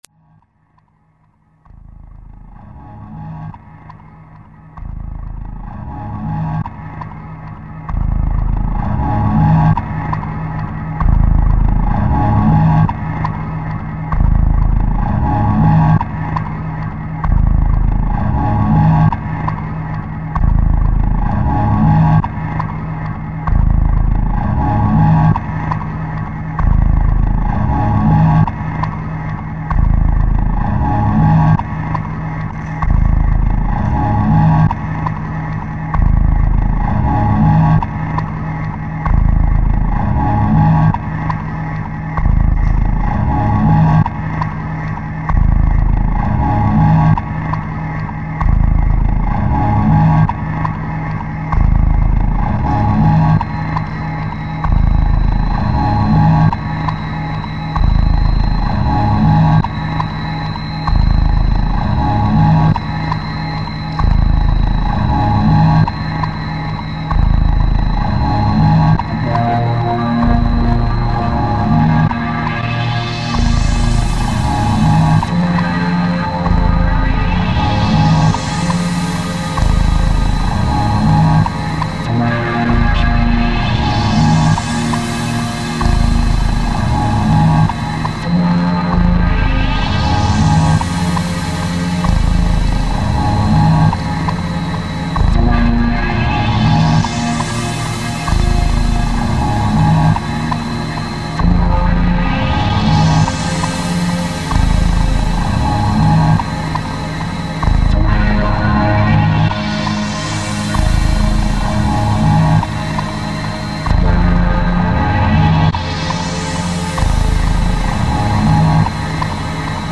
File under: Symbiotic Ambient / Experimental